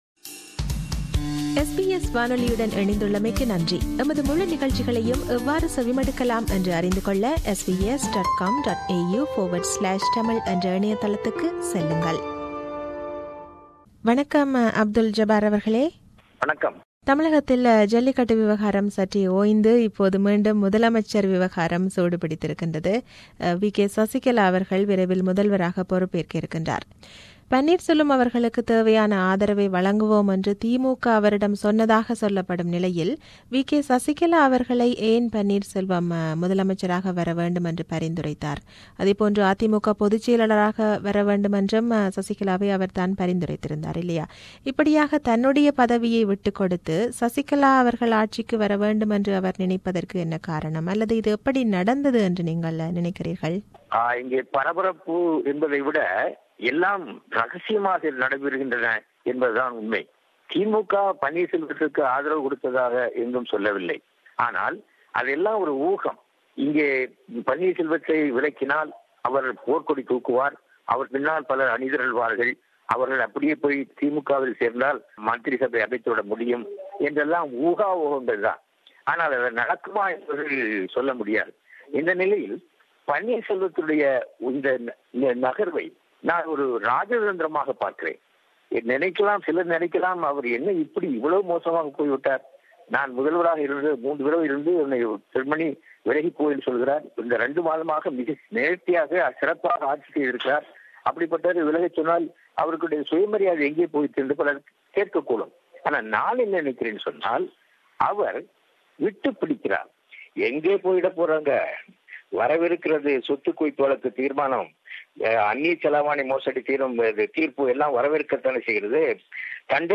an award winning and leading journalist, analyses from Tamil Nadu.